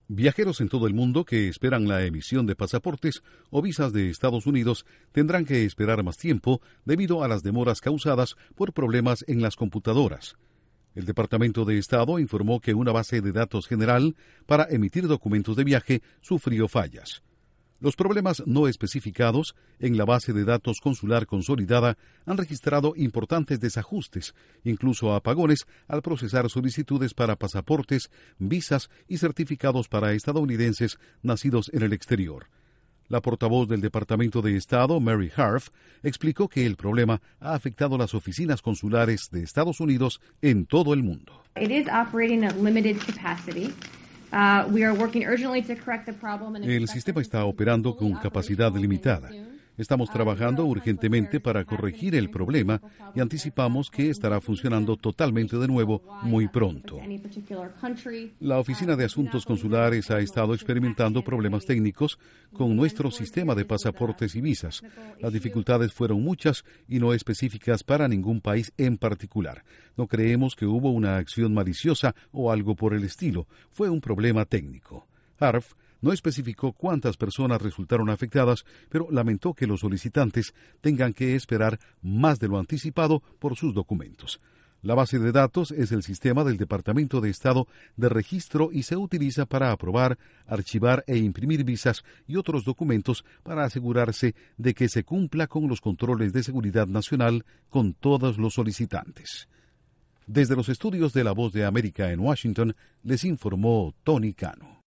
Intro: Estados Unidos demora la emisión de visas y pasaportes, en todo el mundo, debido a una falla técnica. Informa desde los estudios de la Voz de América en Washington